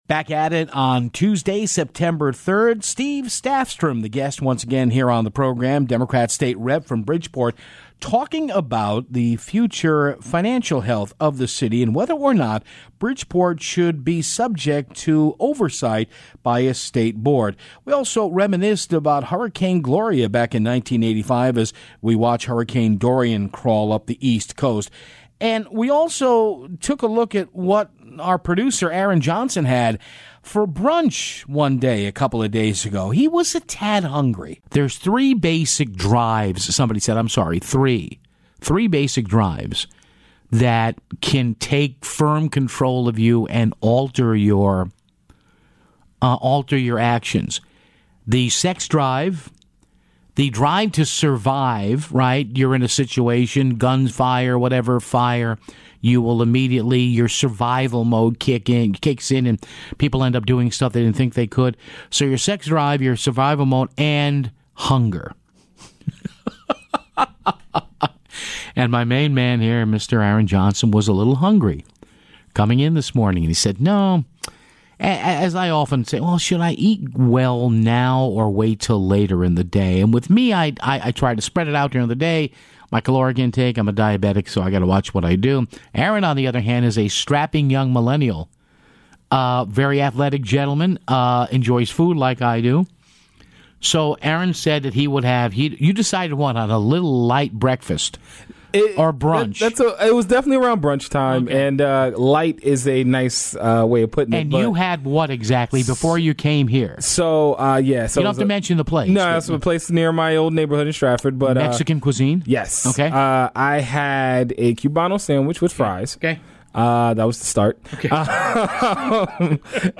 Then, a pair of callers reminisce about Hurricane Gloria from Sept. 1985 and the damage that storm did, as Hurricane Dorian heads up the east coast.